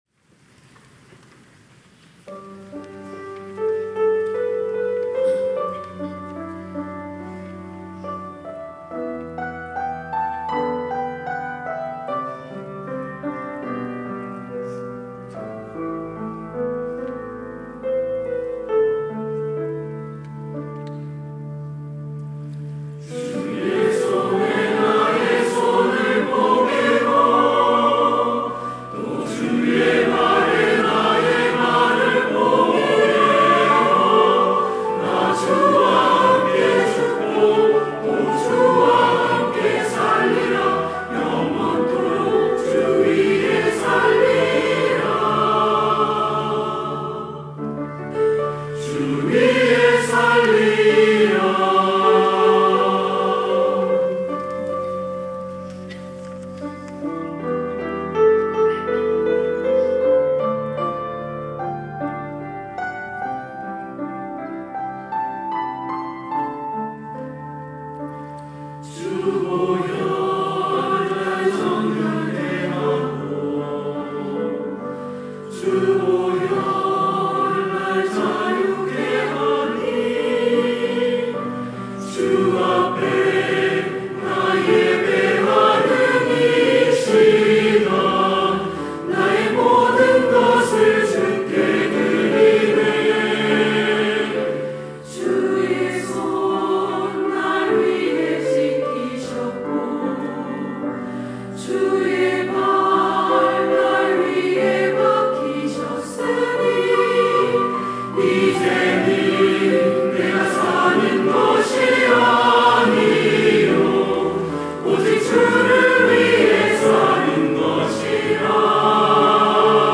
시온(주일1부) - 주의 손에 나의 손을 포개고
찬양대